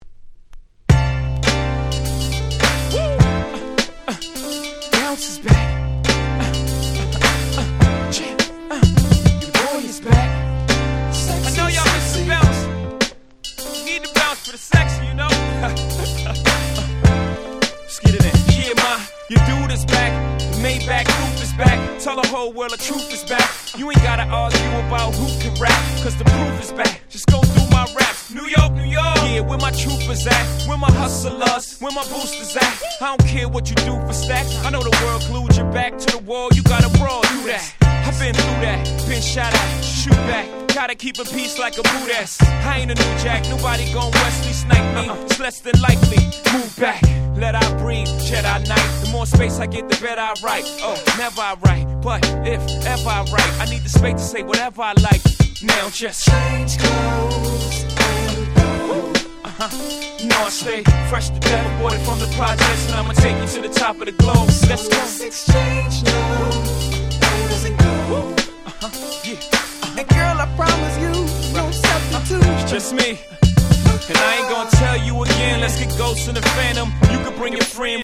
03' Smash Hit Hip Hop !!